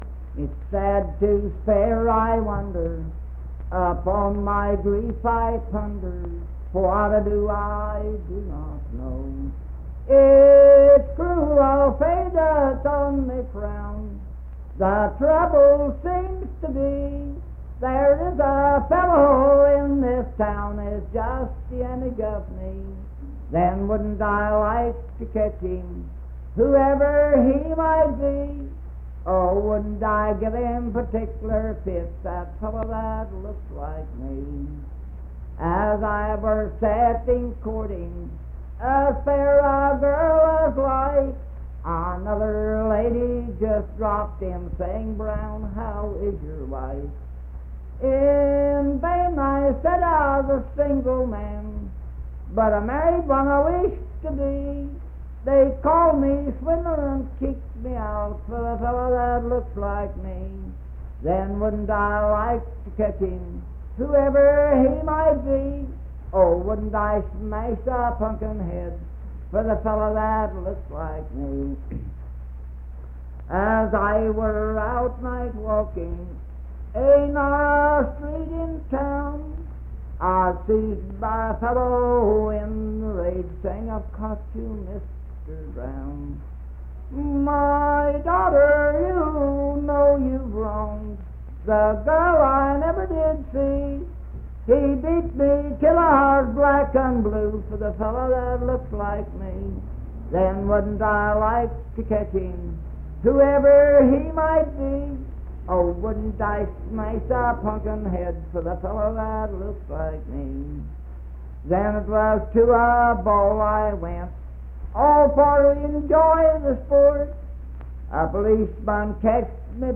Unaccompanied vocal music performance
Voice (sung)
Roane County (W. Va.), Spencer (W. Va.)